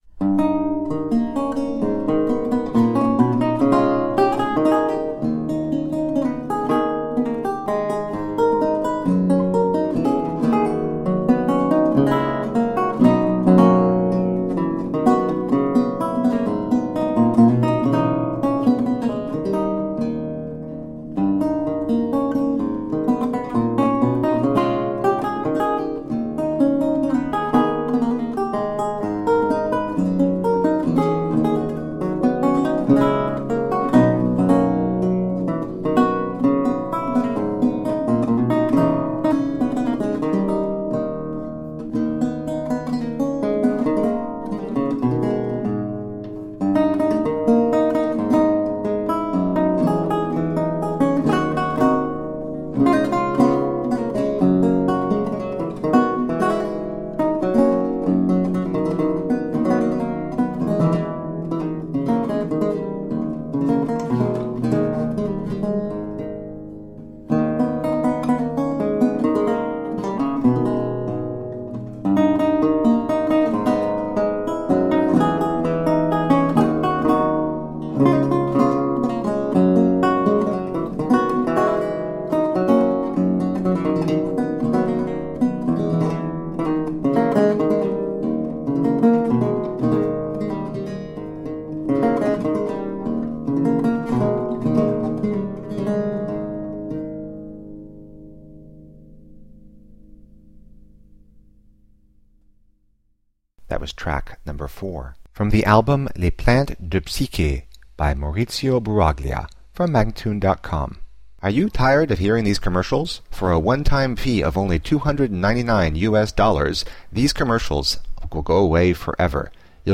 A marvelous classical spiral of lute sounds.
Classical, Baroque, Instrumental Classical
Lute